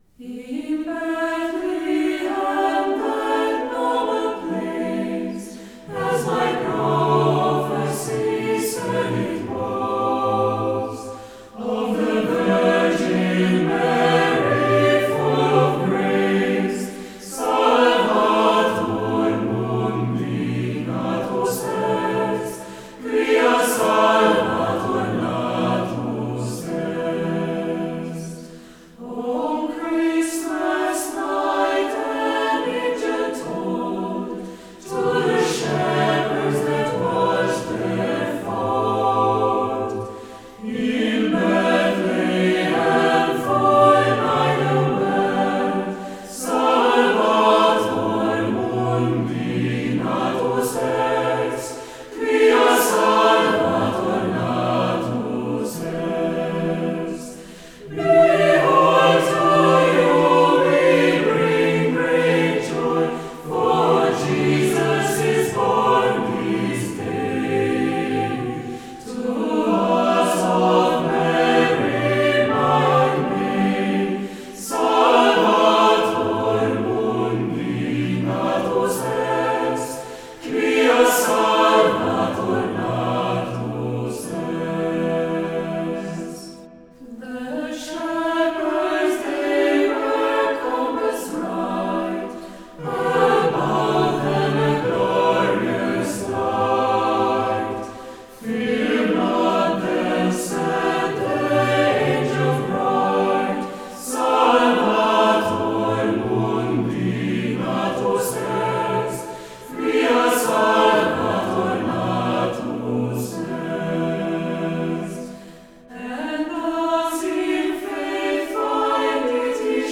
Mixed Choir Tempo - Medium BPM - 87